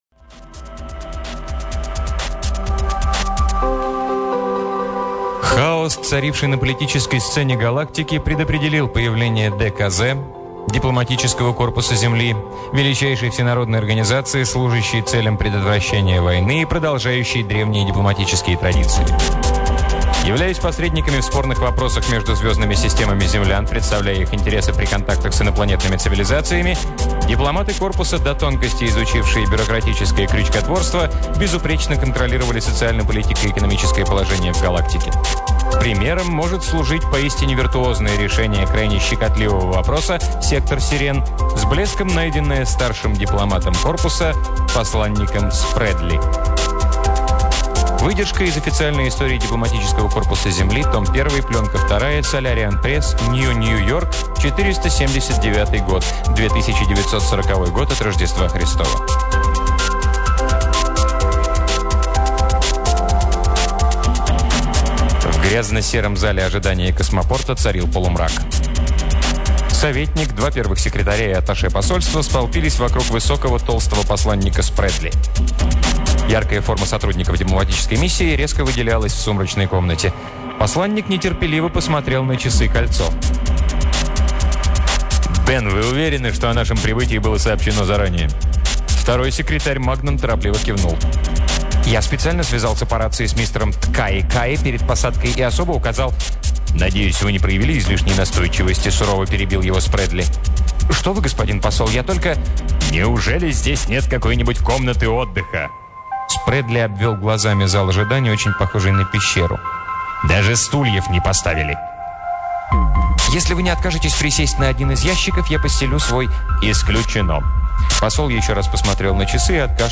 Аудиокнига Кит Лаумер — Протокол